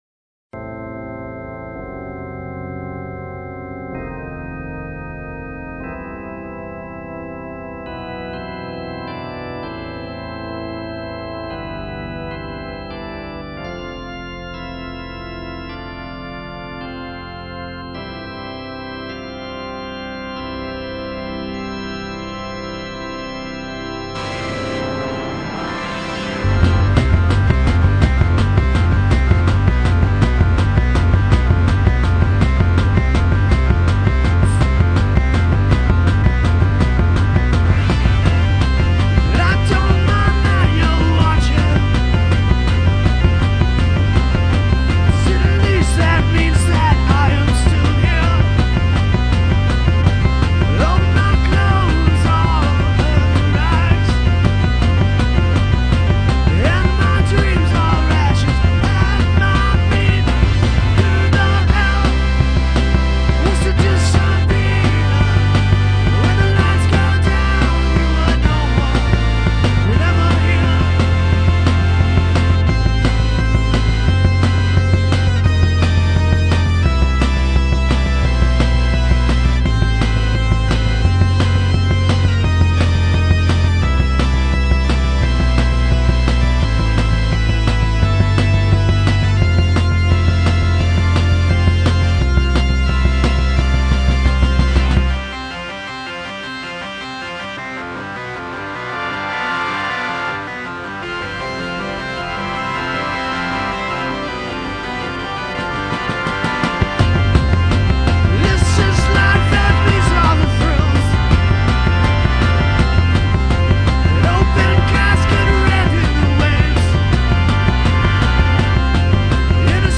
We Recorded this in New Orleans also..
one man industrial band